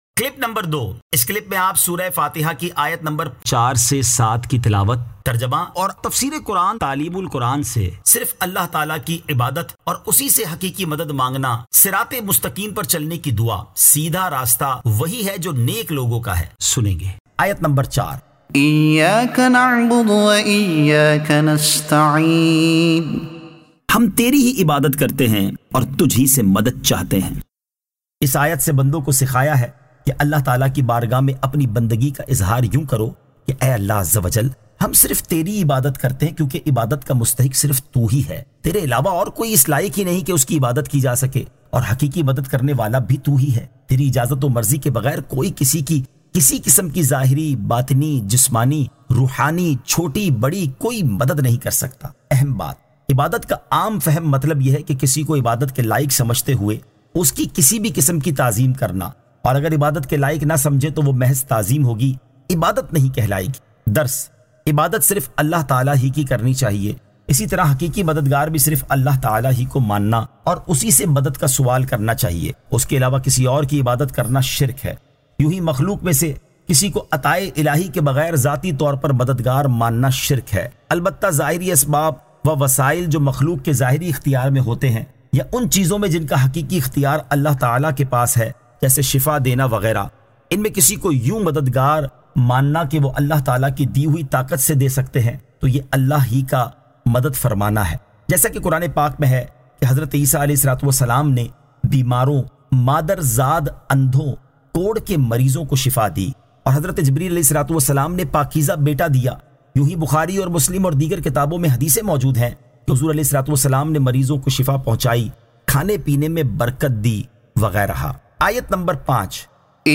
Surah Al-Fatiha Ayat 04 To 07 Tilawat , Tarjuma , Tafseer e Taleem ul Quran